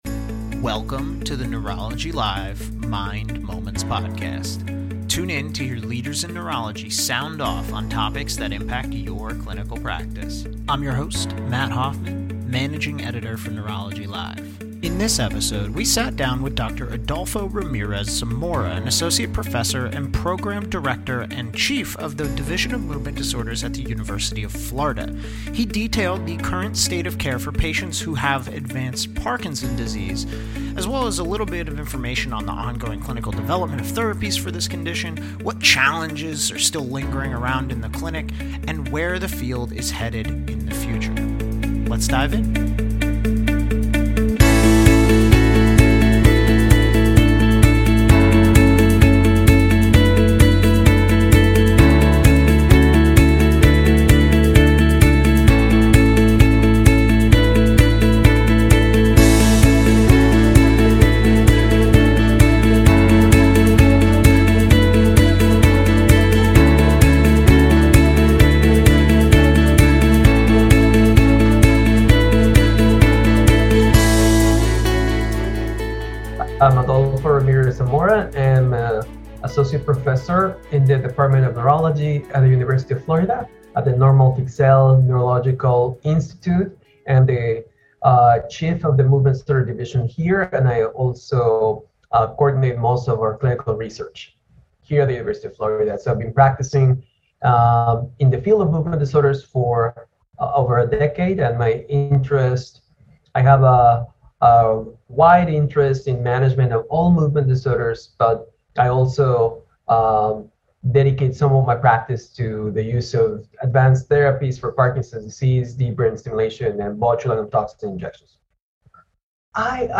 Welcome to the NeurologyLive Mind Moments podcast. Tune in to hear leaders in neurology sound off on topics that impact your clinical practice.